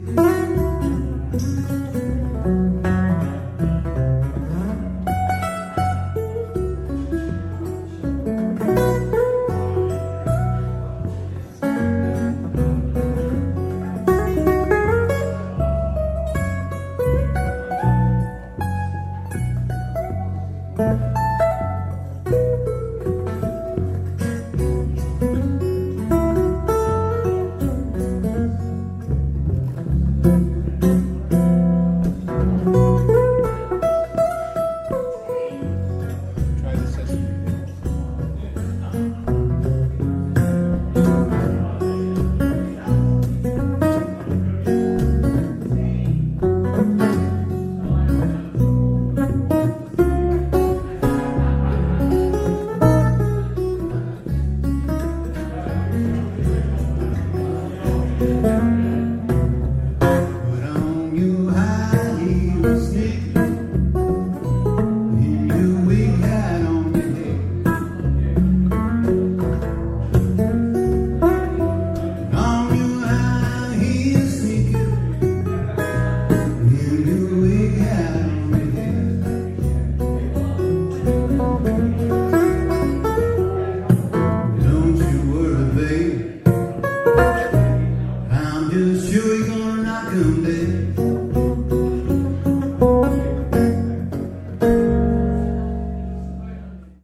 cittern steel
They don't have less volume but rather a more mid centered bandwidth, which I find is excellent for performing through a piezo pickup as in the following live recording.
Archtop live